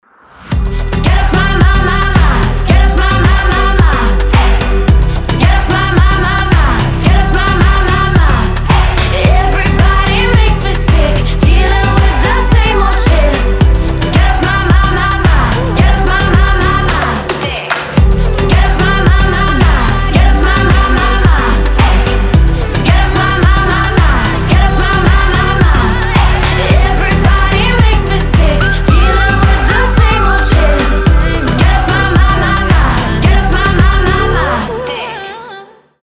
громкие
женский вокал
заводные
Dance Pop
энергичные